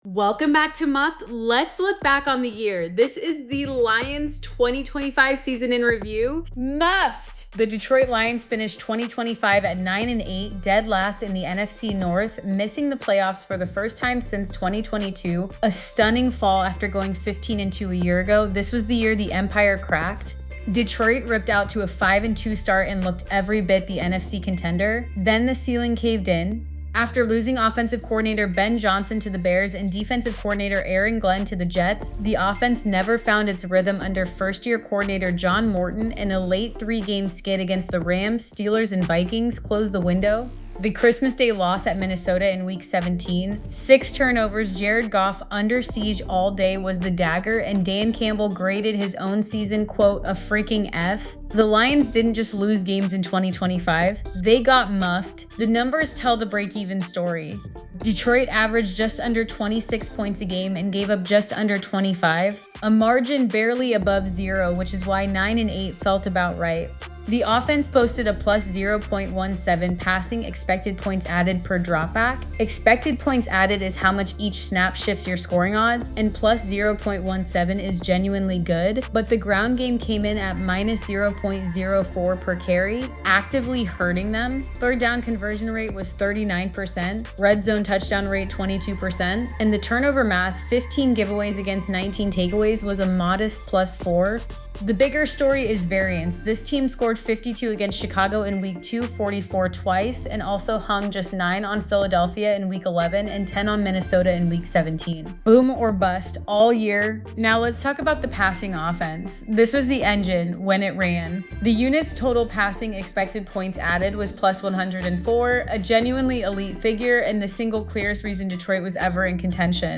Team · 2025 Season ReviewDET
VoiceHot takes and fillerSmart football friend who watched every snap
Personality, energy, and confidence.